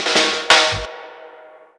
Ragga_Roll.wav